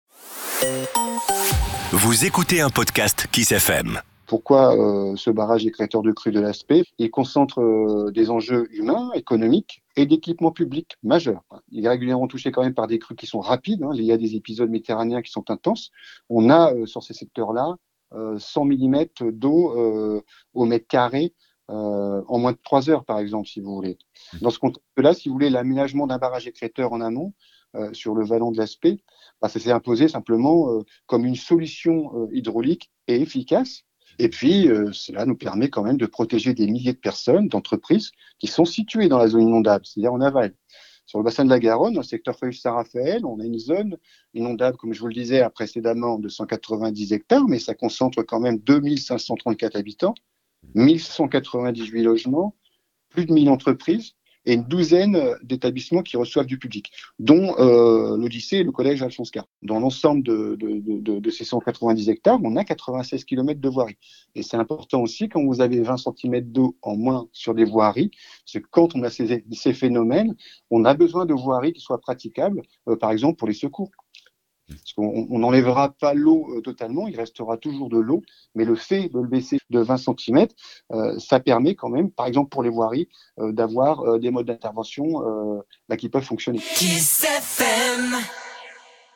Didier Lemaître, Vice Président d’Estérel Côte d’Azur Agglomération, délégué à la GEMAPI nous raconte :